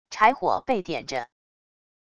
柴火被点着wav音频